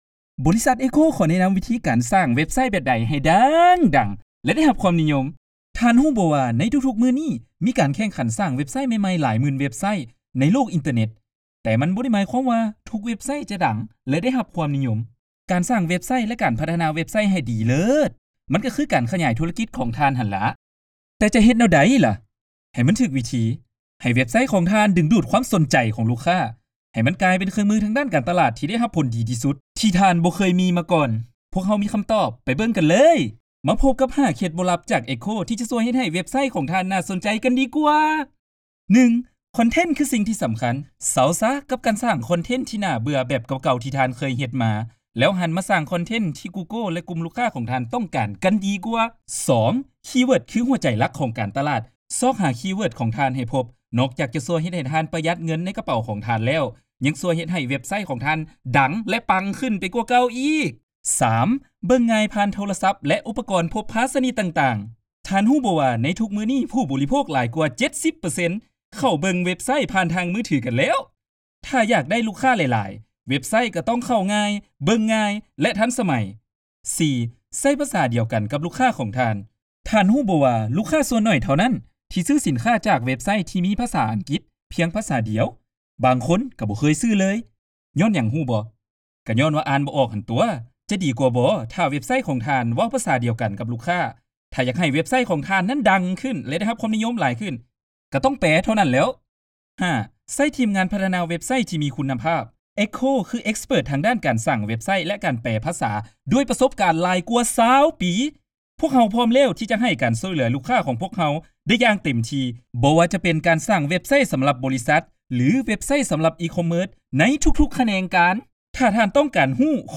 Voiceover Artists
EQHO provides multi-language solutions from its in-house recording facilities
Laos Male
COMMERCIAL